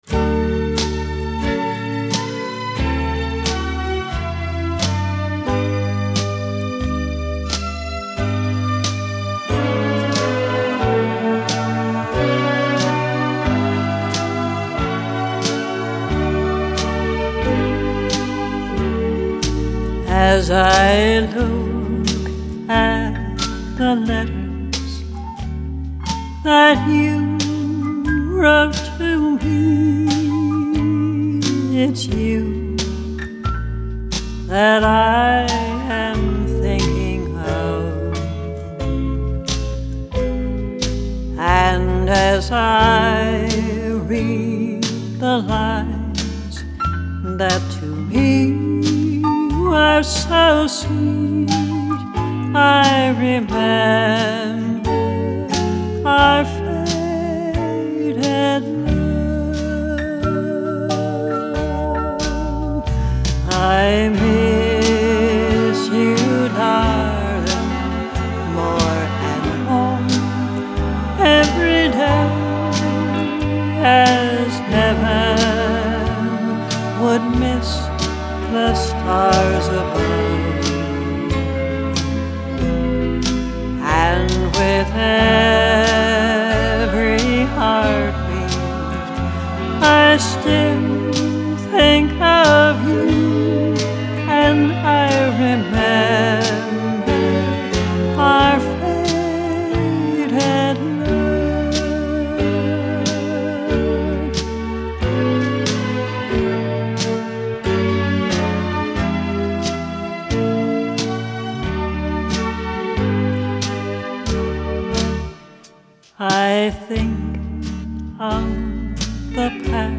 Recorded at The Camp Studio, Hershey, PA - Copyright 2008